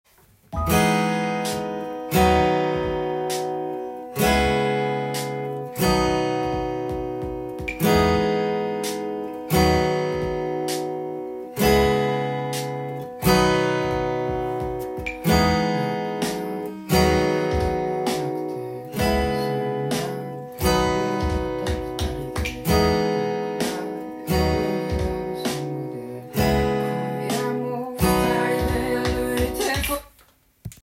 音源に合わせて譜面通り弾いてみました
カポタストを４フレットに付けて弾く
リズムの方は、２小節毎にシンコペーションが入ってきているので